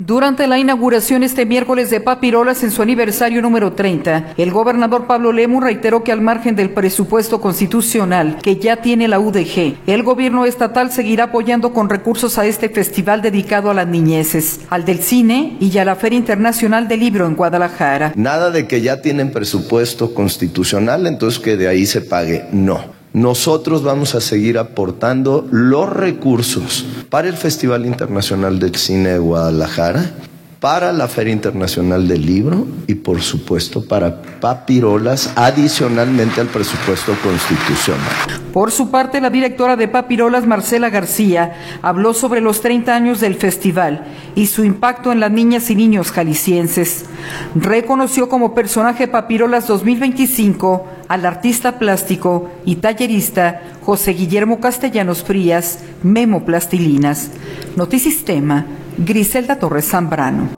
audio Durante la inauguración este miércoles de Papirolas en su aniversario número 30, el gobernador Pablo Lemus reiteró que al margen del presupuesto constitucional que ya tiene la UdeG, el gobierno estatal seguirá apoyando con recursos a este festival dedicado a las niñeces, al de cine y a la Feria Internacional del Libro en Guadalajara.